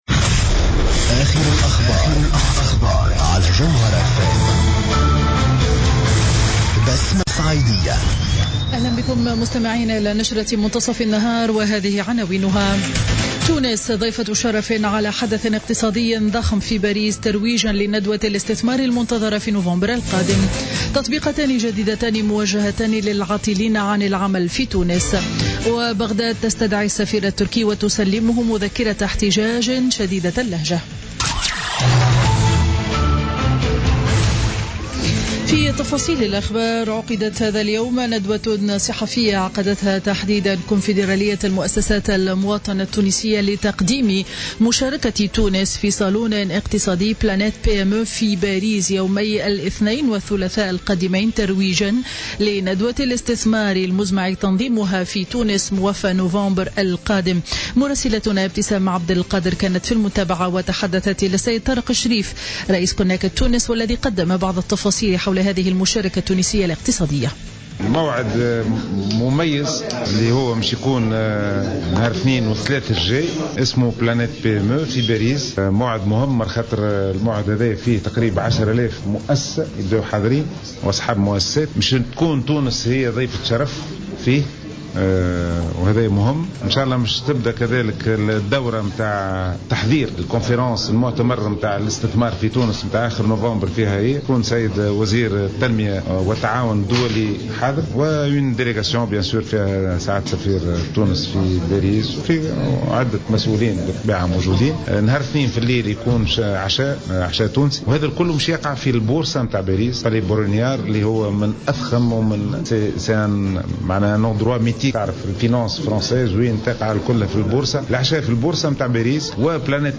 نشرة أخبار منتصف النهار ليوم الخميس 13 أكتوبر 2016